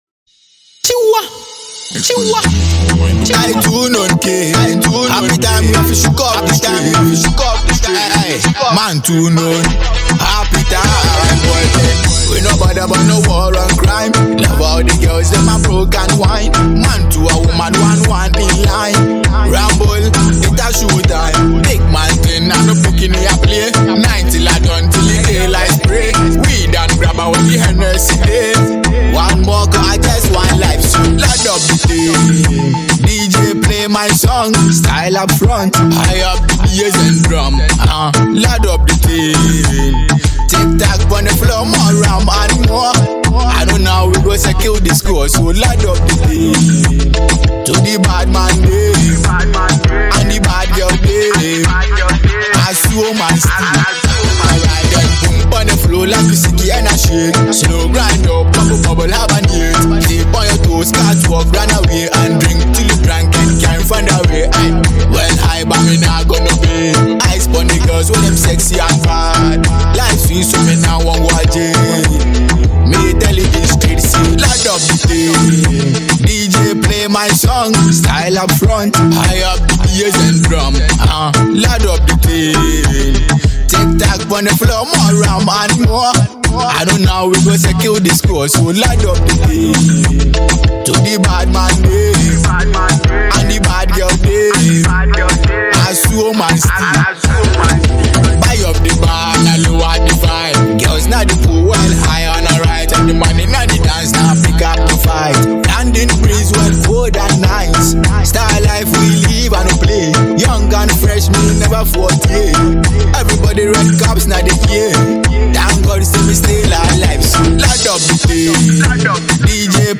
UK based Ghanaian Reggae/Dancehall cum Afrobeats artiste